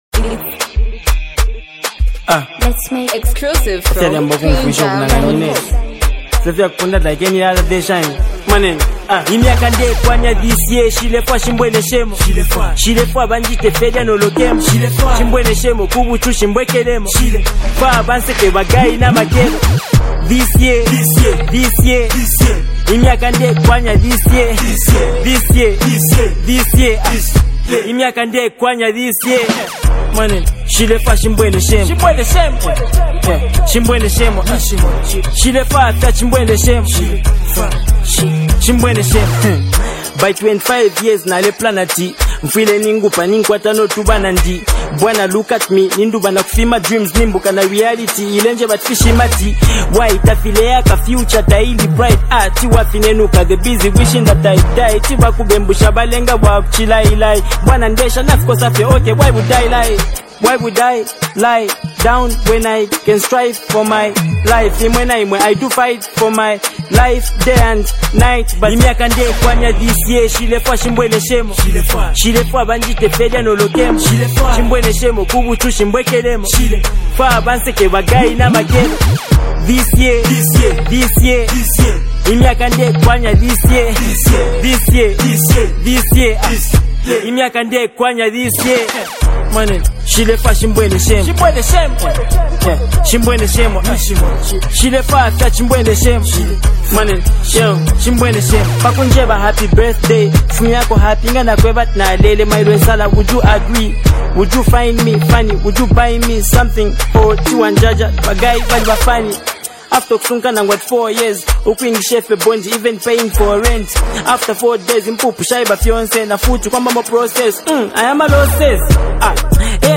Zambian hip-hop continues to thrive
The beat doesn’t overpower the message—instead
he sticks to what he does best—real rap with substance.